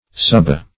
Search Result for " subah" : The Collaborative International Dictionary of English v.0.48: Subah \Su"bah\ (s[=oo]"b[.a]), n. [Per.